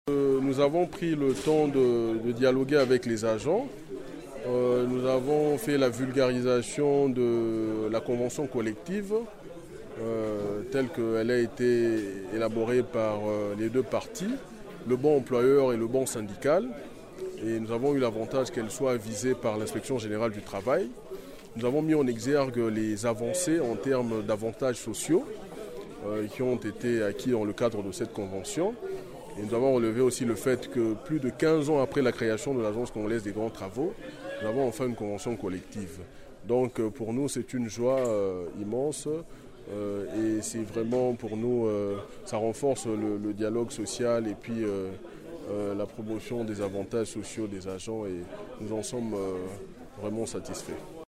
vtr_president_syndical_1-web.mp3